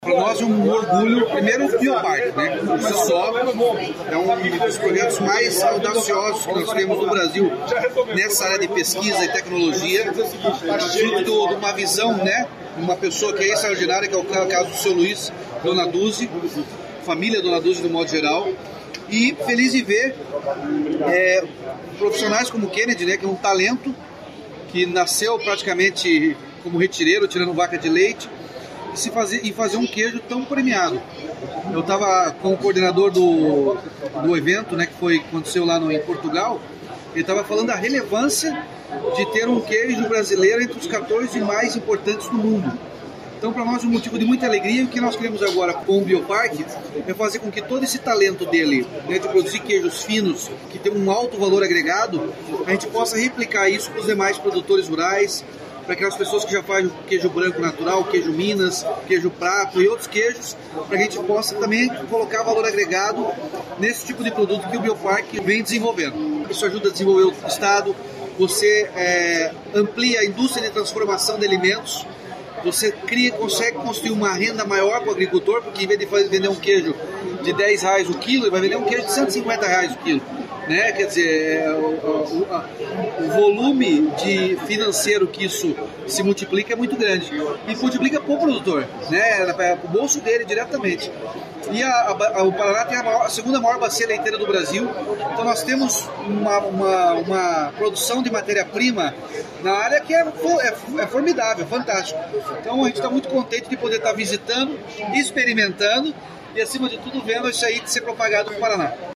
Sonora do governador Ratinho Junior sobre a parceria com o Biopark para levar projeto de queijos finos a mais produtores